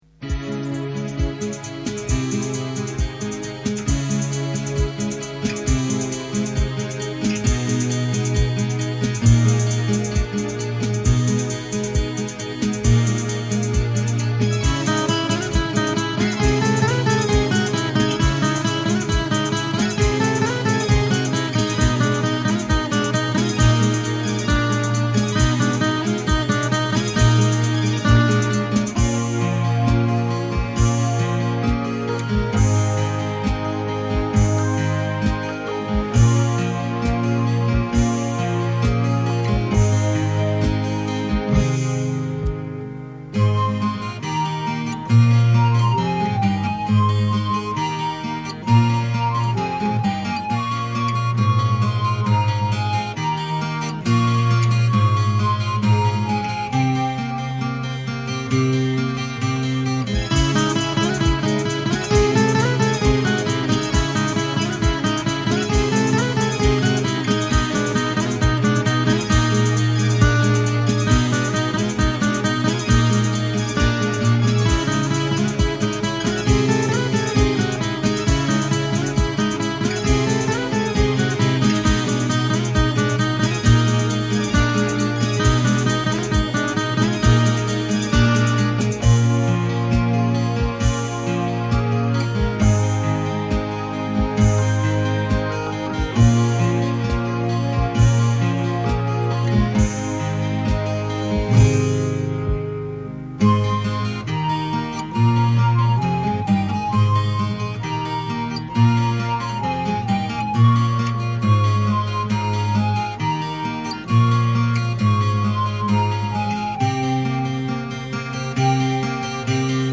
Temps Immobiles - nostalgie euphorique - musiques d'accompagnement de spectacles (poesies, theatre et chansons) produites à cherbourg